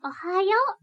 ボイス
キュート女性挨拶